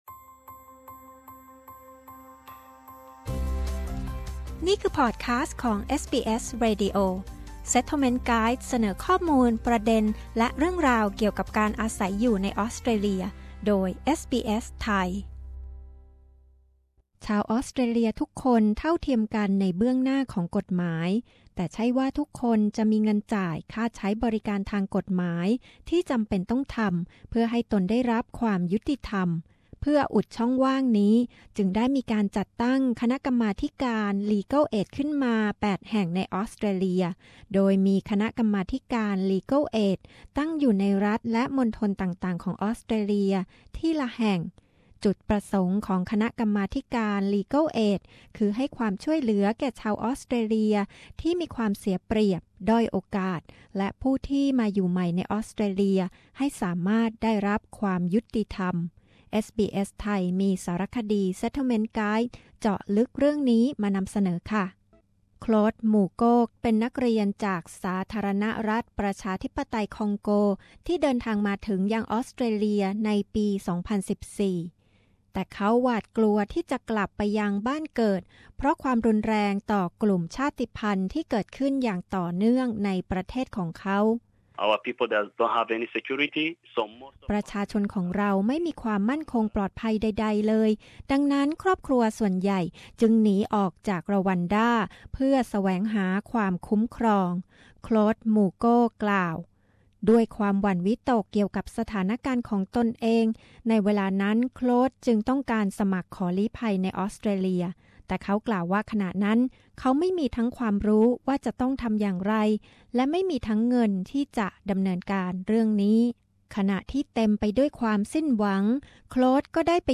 Legal Aid ให้ความช่วยเหลือด้านกฎหมายฟรีแก่ประชาชนในด้านใดบ้าง และใครมีสิทธิได้รับความช่วยเหลือเหล่านี้ ติดตามได้จากรายงานพิเศษเรื่องนี้จากเอสบีเอส ไทย